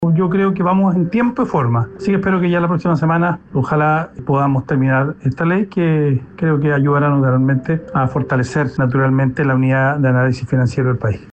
Si bien se ha diferenciado de gran parte de la oposición, el diputado y jefe de bancada de la UDI, Henry Leal, señaló que por el escaso margen de tiempo, el proyecto de ley no alcanzará a definirse pronto.